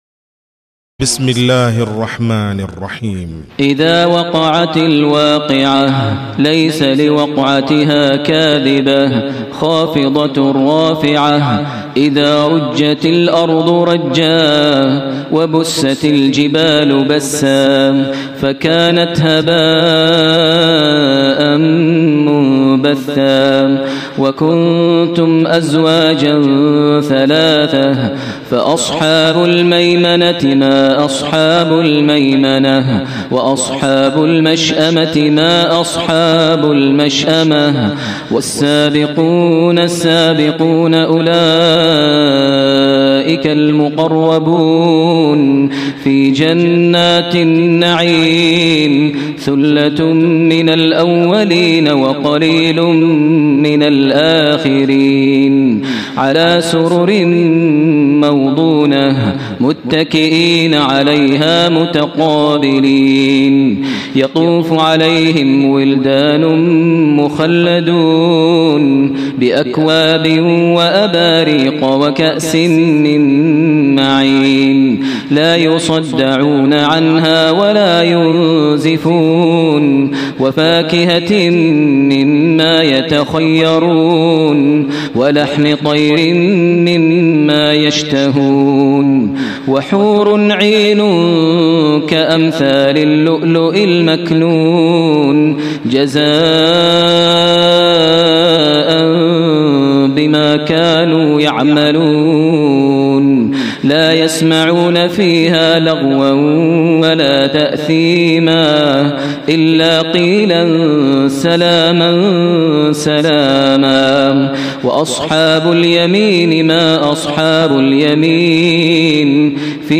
تهجد ليلة 30 رمضان 1431هـ من سور الواقعة و المعارج و القيامة Tahajjud 30 st night Ramadan 1431H from Surah Al-Waaqia and Al-Ma'aarij and Al-Qiyaama > تراويح الحرم المكي عام 1431 🕋 > التراويح - تلاوات الحرمين